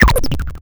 mystical.wav